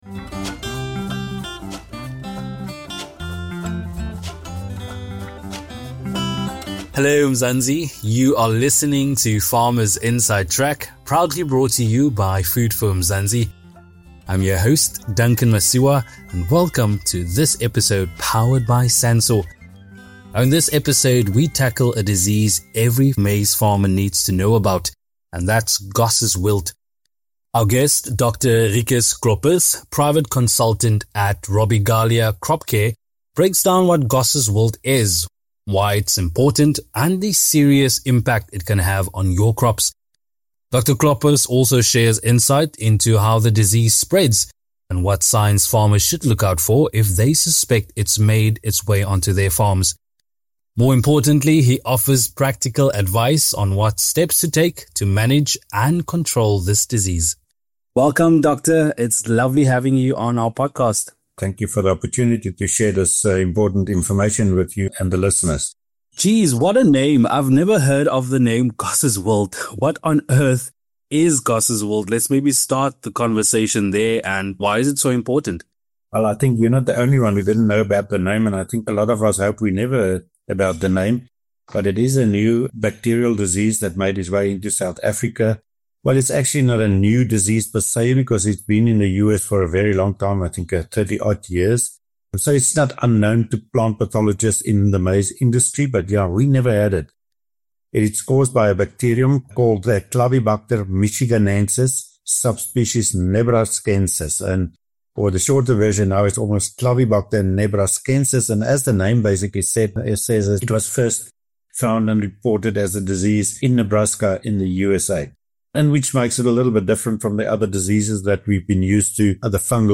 SANSOR-INTERVIEW-1-FINAL.mp3